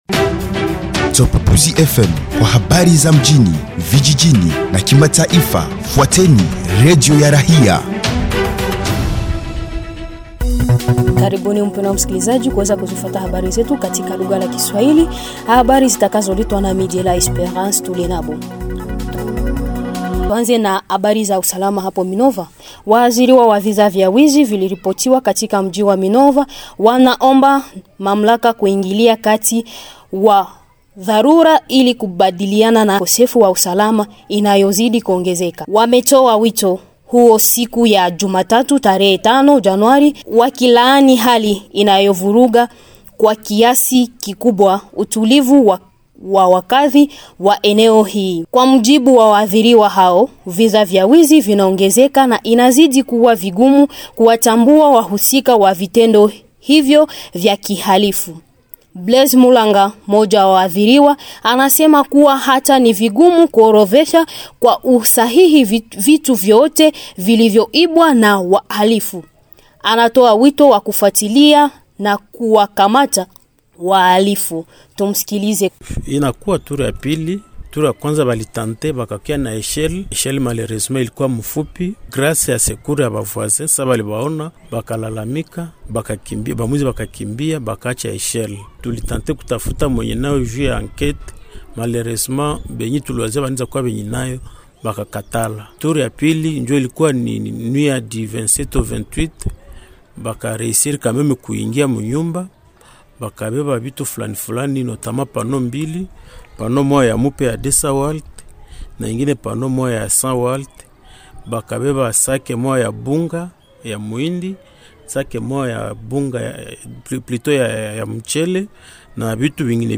Journal Swahili soir 18h30 5 janvier 2026